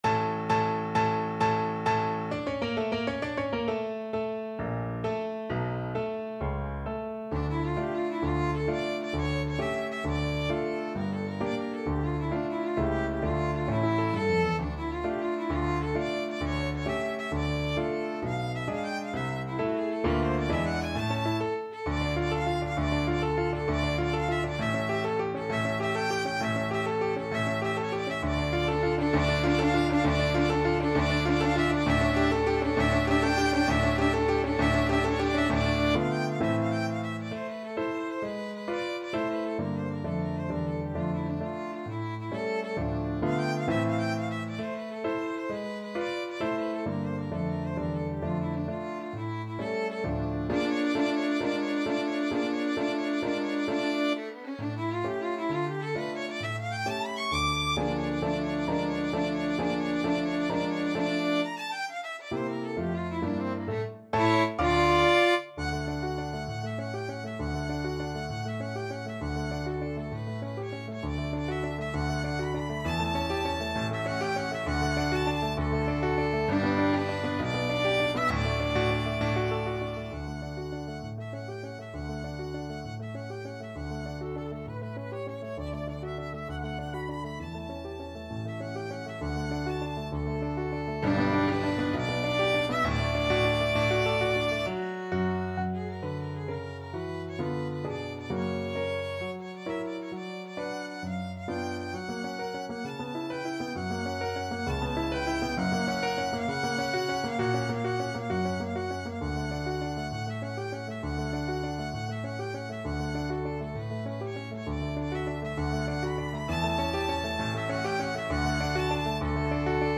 Violin
6/8 (View more 6/8 Music)
D minor (Sounding Pitch) (View more D minor Music for Violin )
~. = 132 Allegro con spirito (View more music marked Allegro)
Classical (View more Classical Violin Music)
squire_tarantella_VLN.mp3